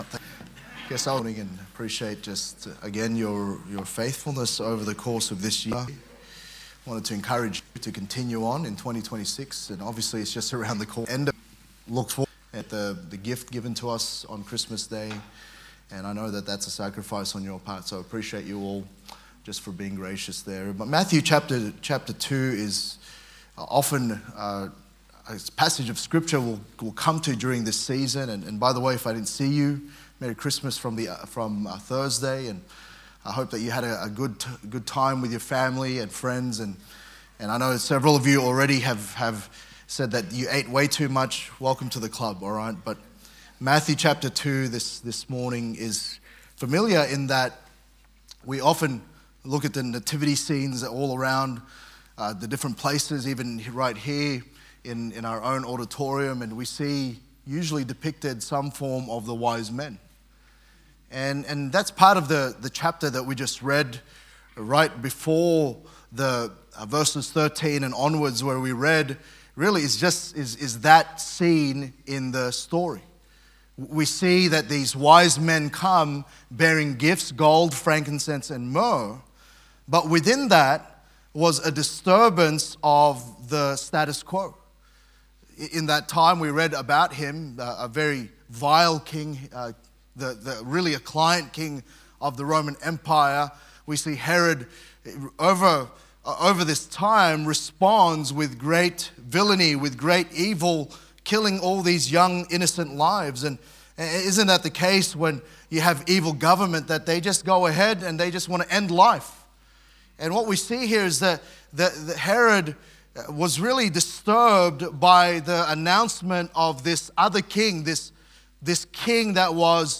Courageous Christmas Current Sermon